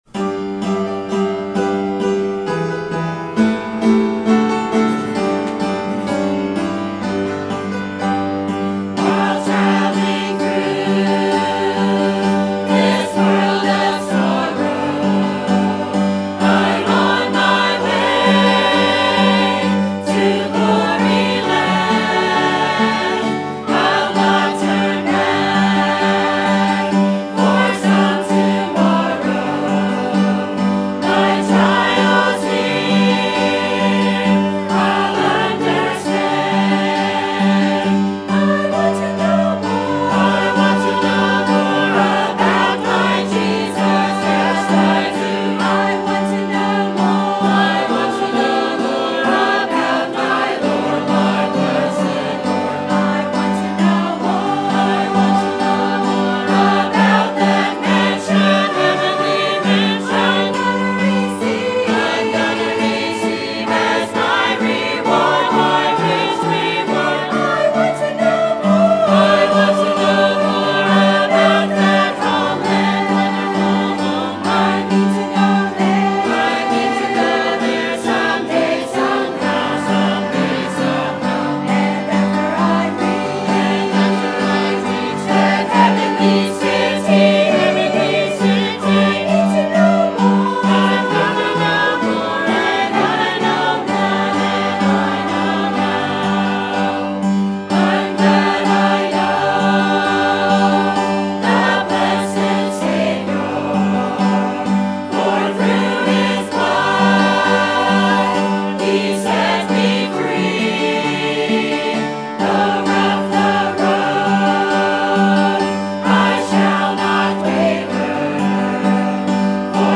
Sermon Type: Series Sermon Audio: Sermon download: Download (27.61 MB) Sermon Tags: Acts Return Paul Call